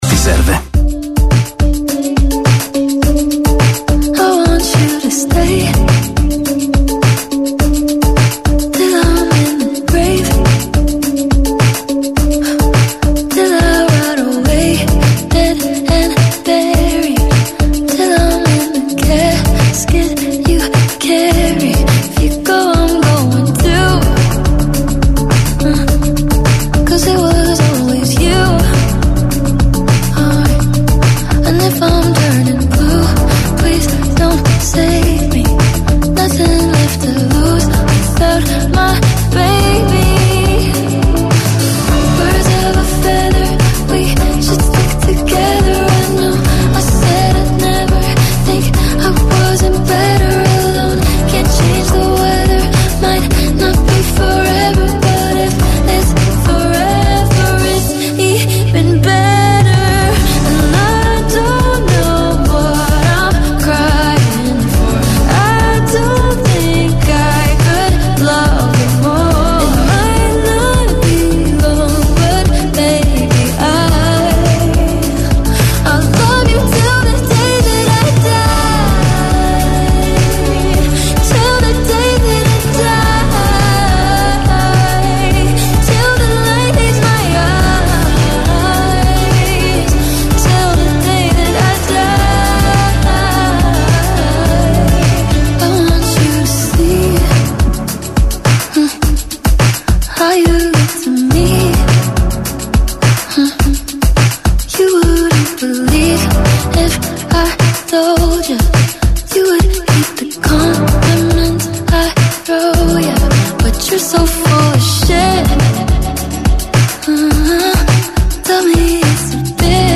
COLLEGAMENTI IN DIRETTA CON I CANALI TV ALL NEWS, OSPITI AL TELEFONO DAL MONDO DELLO SPETTACOLO, DELLA MUSICA, DELLA CULTURA, DELL’ARTE, DELL’INFORMAZIONE, DELLA MEDICINA, DELLO SPORT E DEL FITNESS.